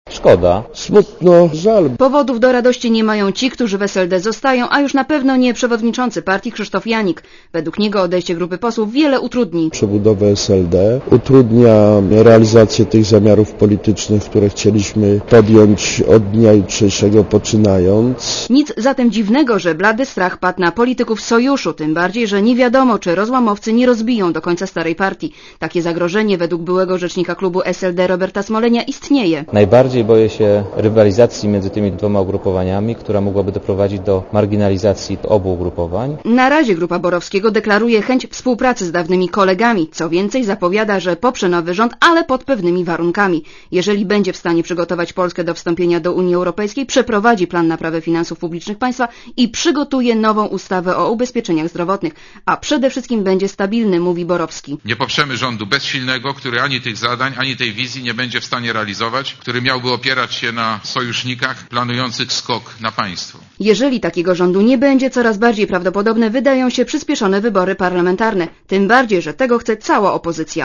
Posłuchaj relacji reporterki Radia Zet